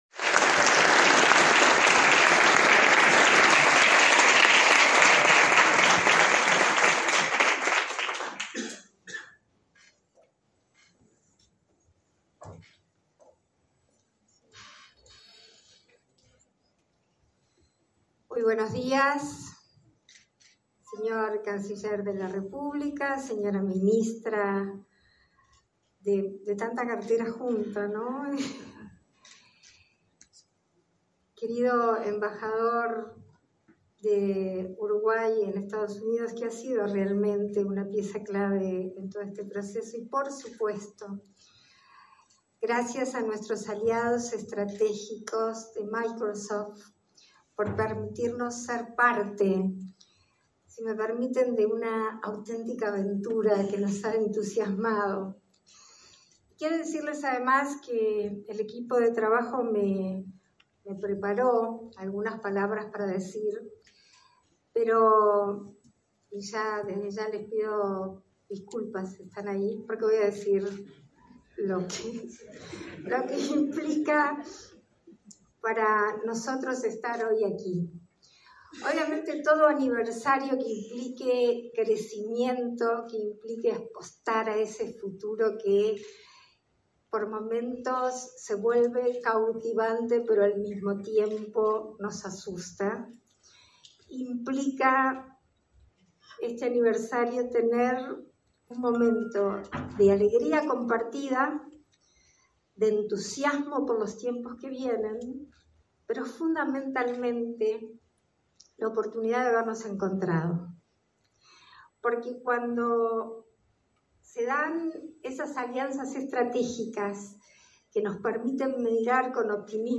Palabras de la vicepresidenta de la República, Beatriz Argimón
Palabras de la vicepresidenta de la República, Beatriz Argimón 03/06/2024 Compartir Facebook X Copiar enlace WhatsApp LinkedIn La vicepresidenta de la República, Beatriz Argimón, participó, este 3 de junio, en el primer aniversario del laboratorio de Inteligencia Artificial de Microsoft, Microsoft AI Co-Innovation Lab.